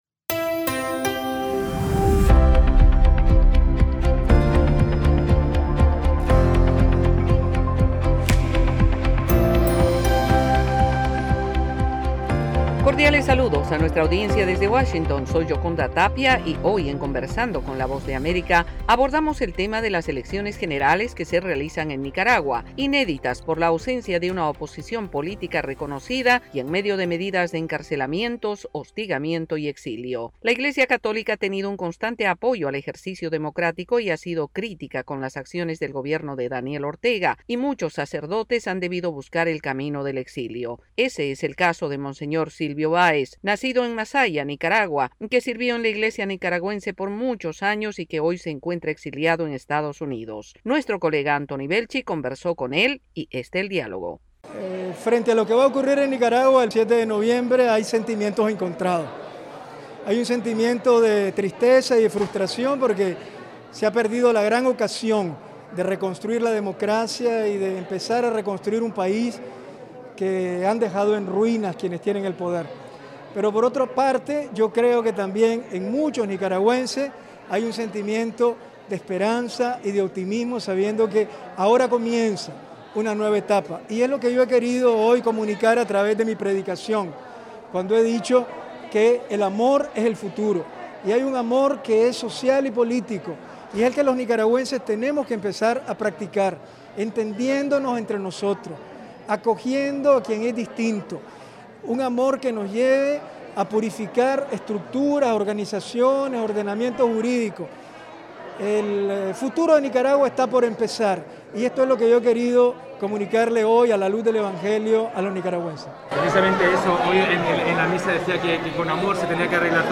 Conversamos con monseñor Silvio Baéz, sacerdote nicaragüense que se encuentra en el exilio luego del hostigamiento y amenazas del gobierno de Daniel Ortega.